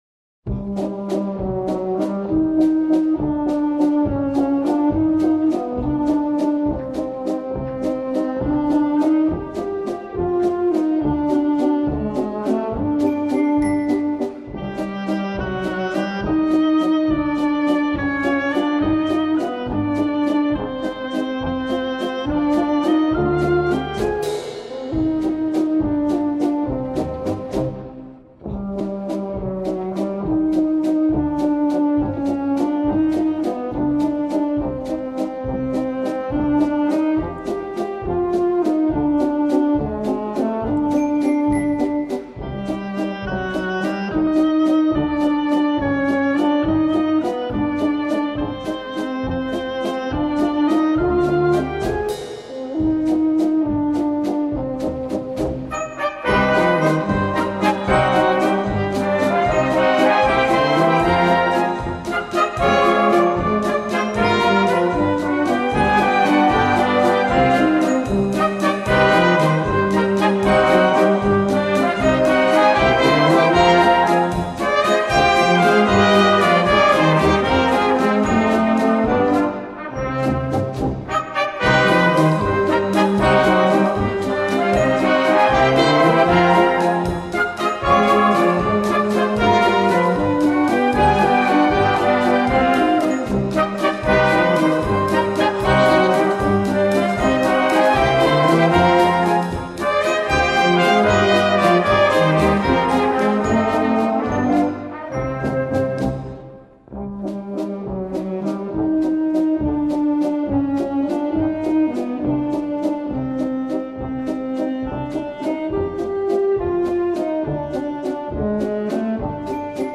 Предлагаю небольшой вечерний концерт старых вальсов в исполнении духовых оркестров.